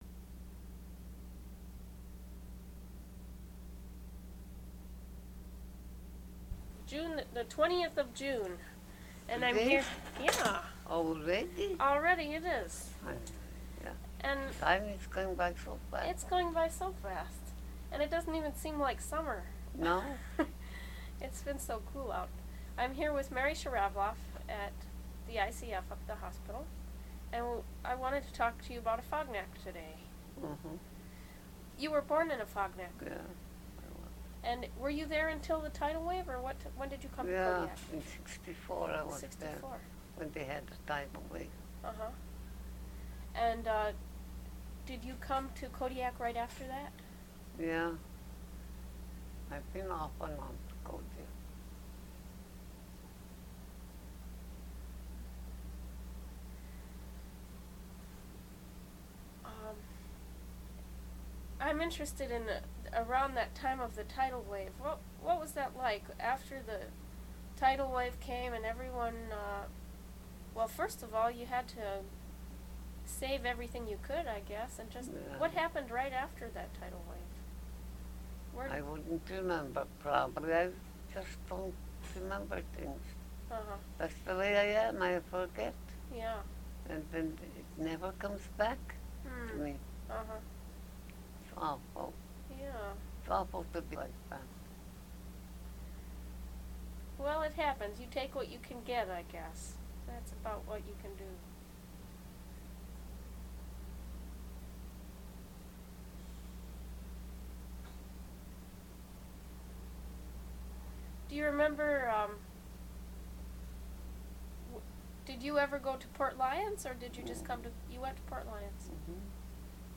Quality: good. (In English) Location: Location Description: Kodiak, Alaska